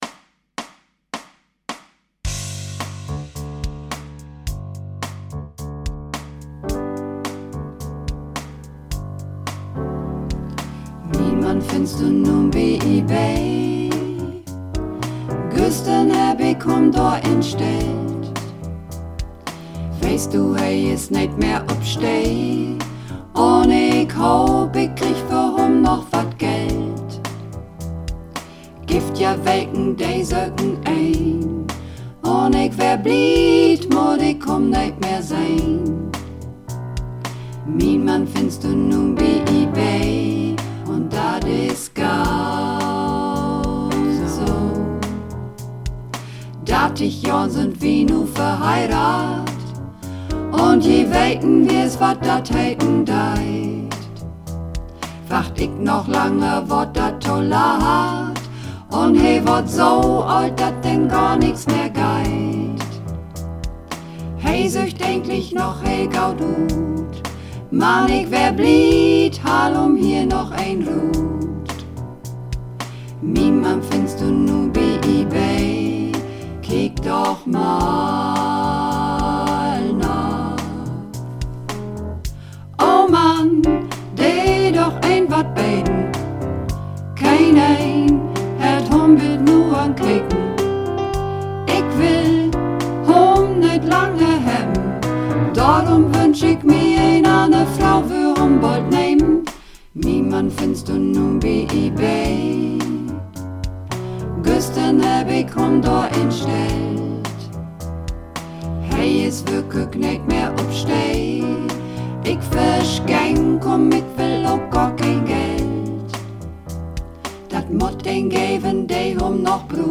Übungsaufnahmen - Ebay
Runterladen (Mit rechter Maustaste anklicken, Menübefehl auswählen)   Ebay (Mehrstimmig)
Ebay__4_Mehrstimmig.mp3